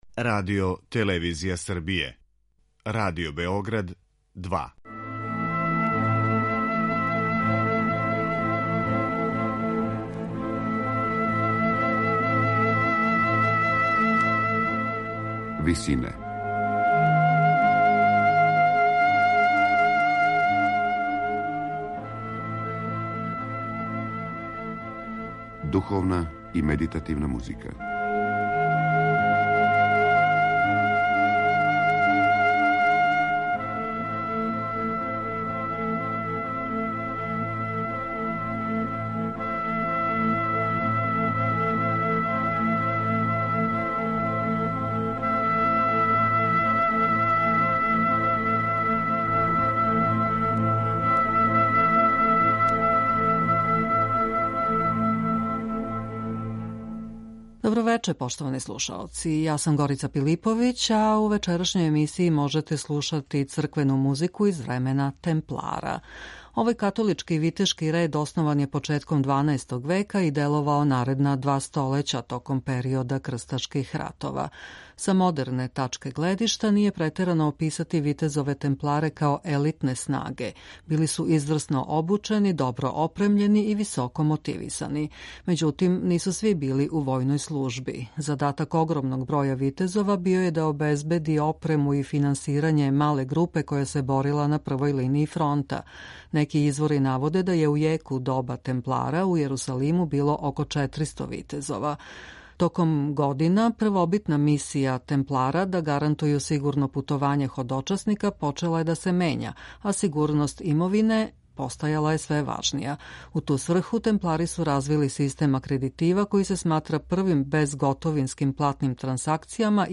Црквена музика у време темплара